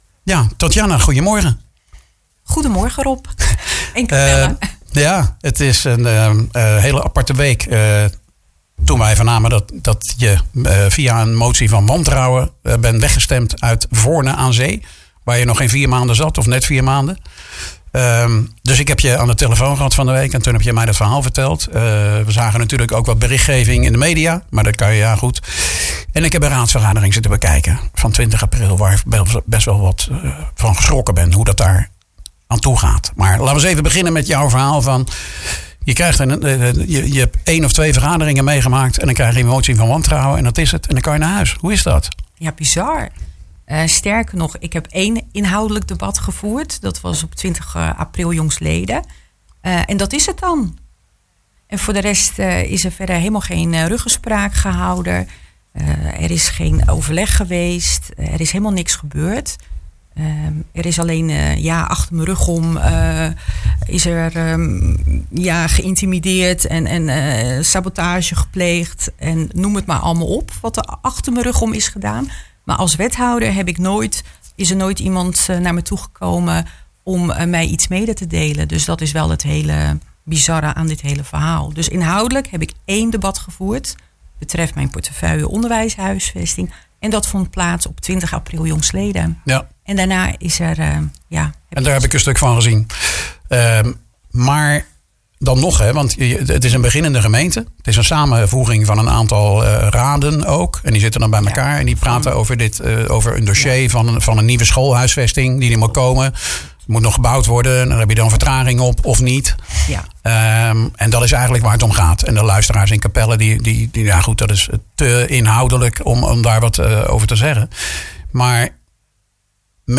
Nu is zij weer in Capelle en hier in de studio.